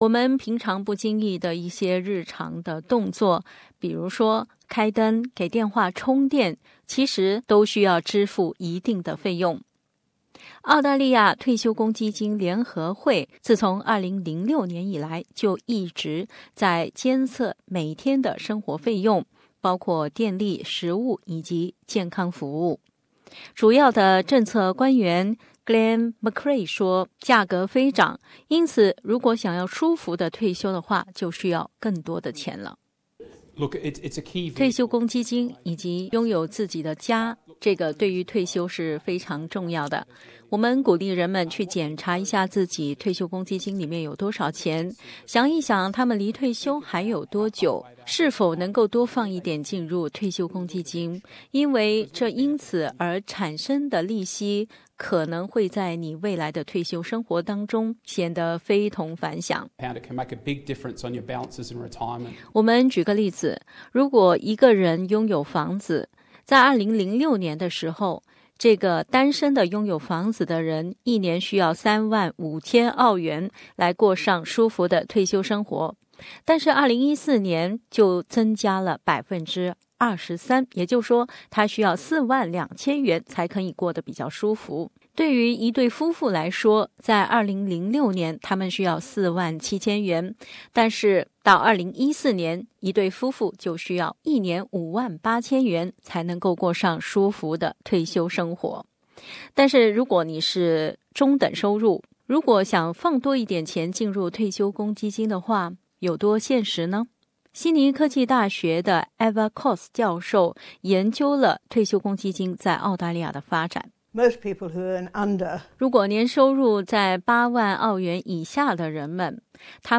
因此该机构建议，现在的人们要把更多的钱放进退休公积金账户。但是，在街访的过程中，并非所有的人对这个观点买账，有人认为，今朝有酒今朝醉，活在当下才是最好。